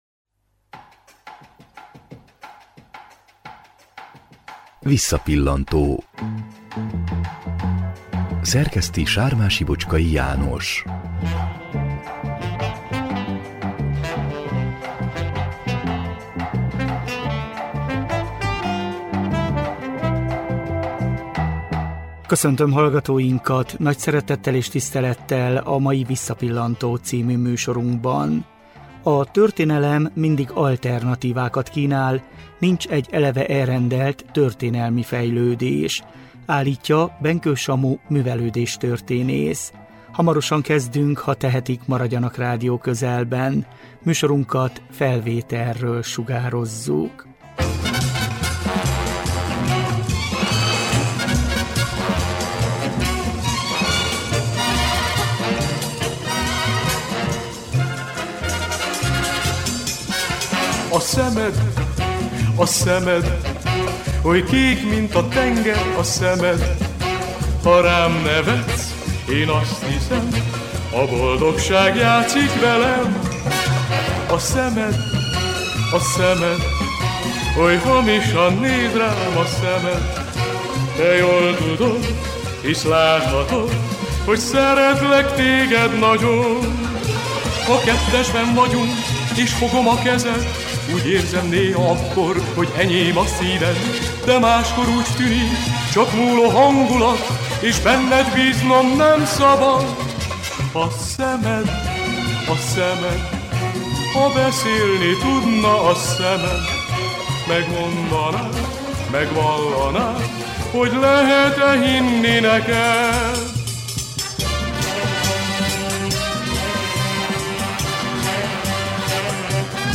1995-ben Erdőszentgyörgyön koncertezett a Republic együttes.